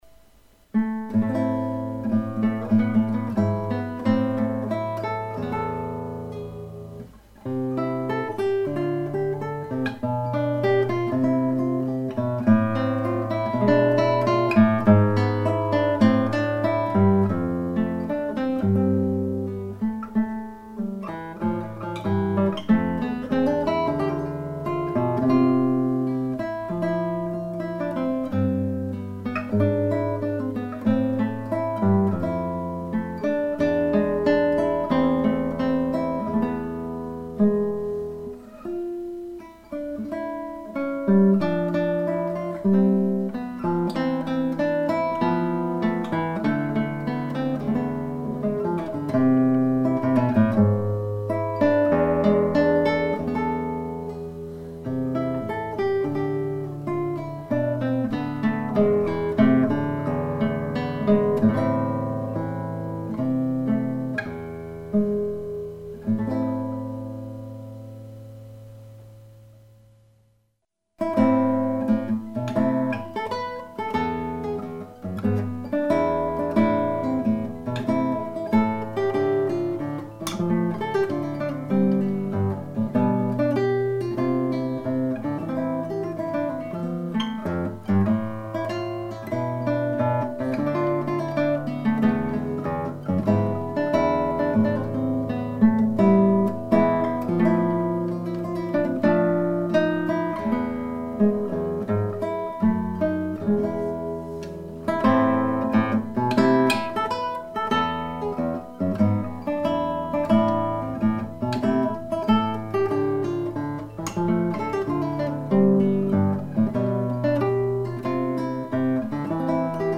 Chitarra Classica
nei tempi Preludio, Allemanda, Giga, Sarabanda, Gavotta